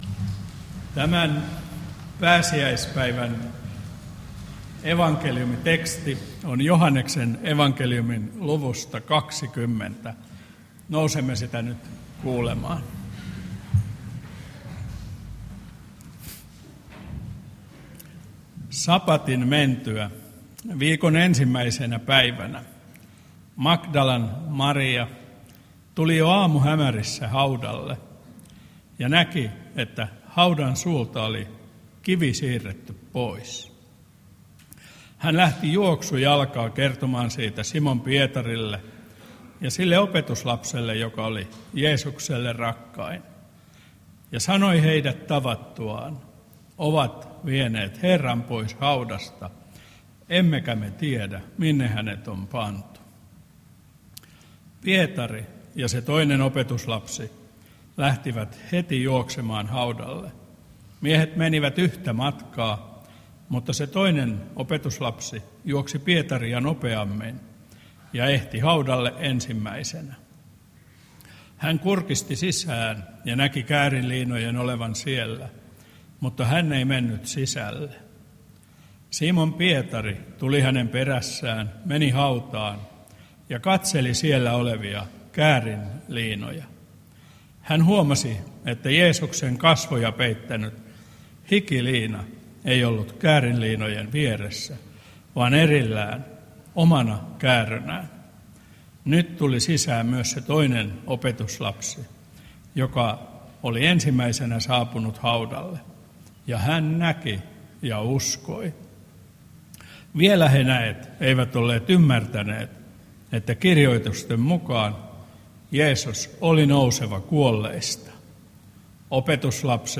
Lahti